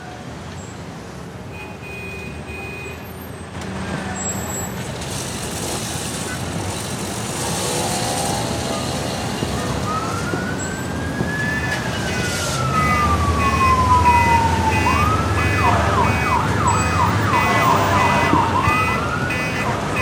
Ambiance Chicago (Broadcast) – Le Studio JeeeP Prod
Bruits d’ambiance d’une ville US avec circulation, sirènes de police, …